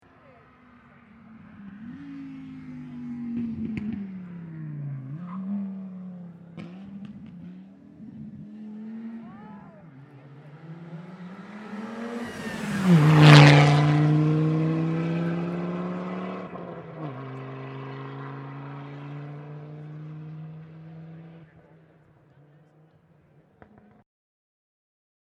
Audi Quattro Gruppe 4 (1982) - Vorbeifahrt am Klausenrennen 2013